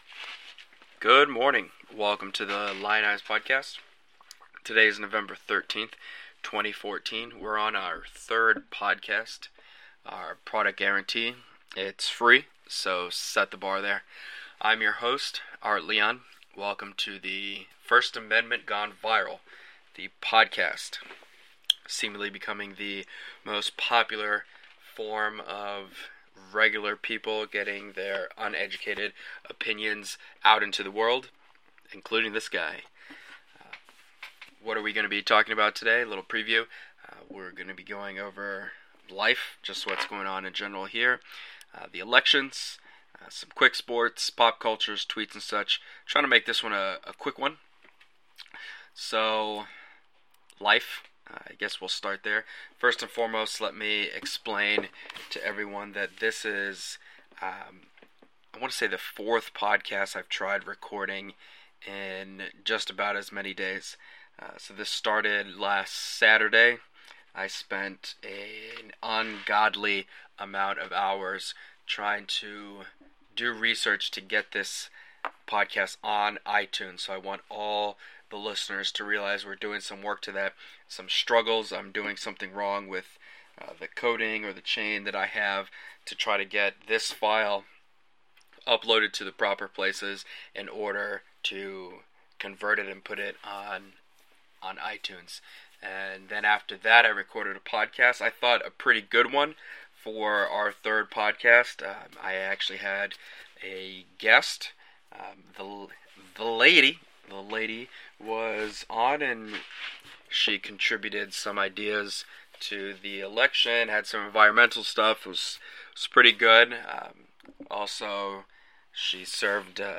in which we chat about nothing...enjoy our banter/outtakes/the reason this went so long. Topics include a comparison of the Lionize to Contact , Ohio, pilots, plus other stupidity.